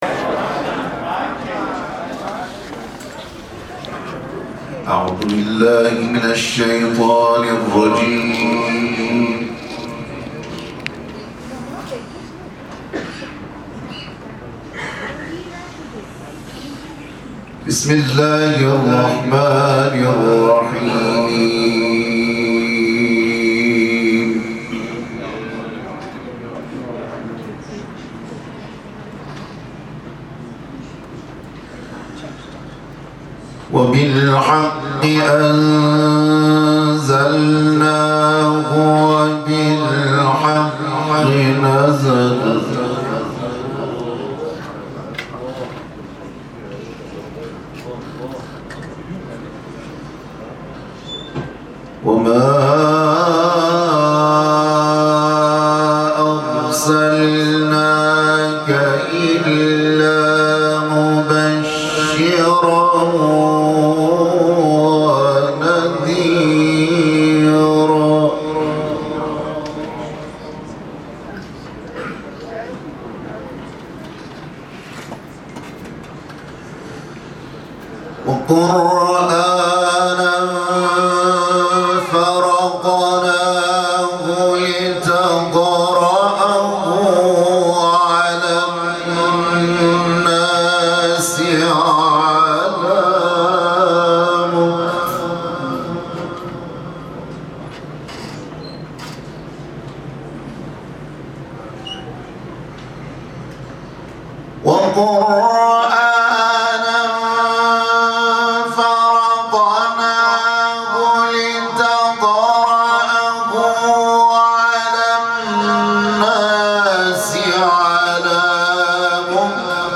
به گزارش ایکنا؛ مراسم تجلیل از نخبگان قرآنی و حائزان رتبه در مسابقات بین‌المللی قرآن کریم روز گذشته 25 آذرماه به همت سازمان بهزیستی کشور برگزار شد.
جدیدترین تلاوت